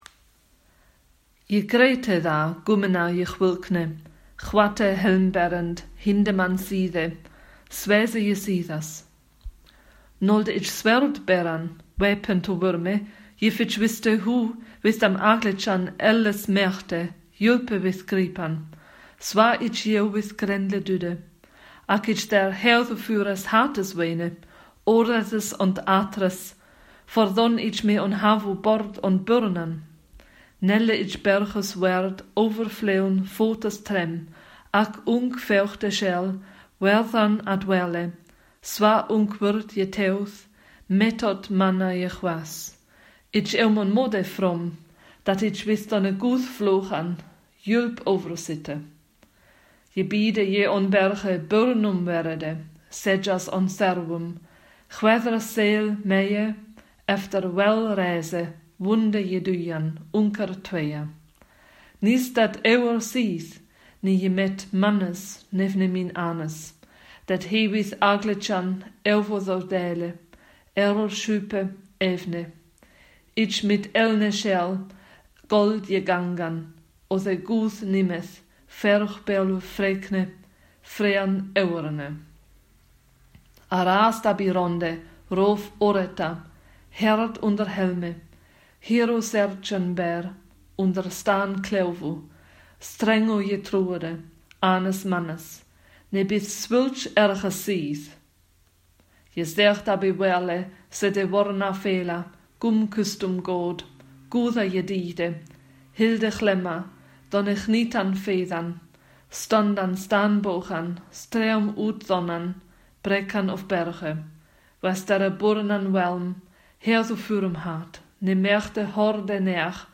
Old English Core Vocabulary: Pronunciation
Soundfiles of Old English Texts being read aloud: